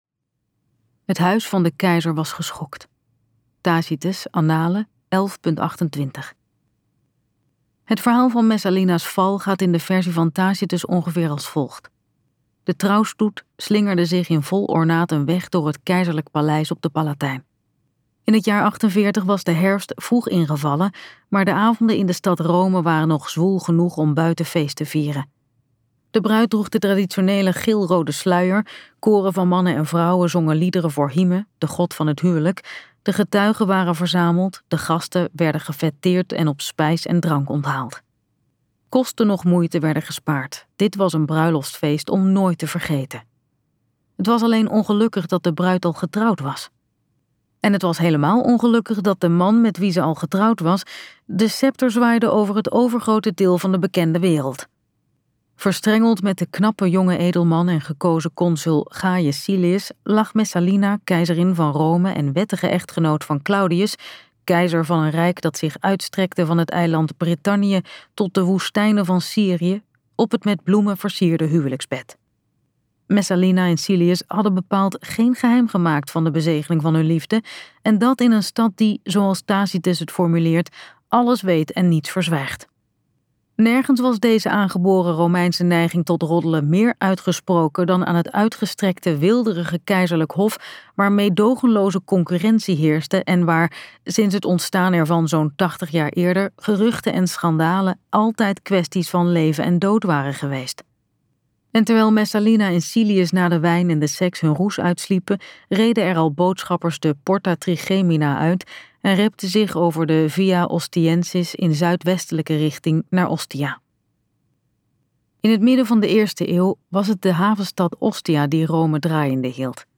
Uitgeverij Omniboek | Messalina luisterboek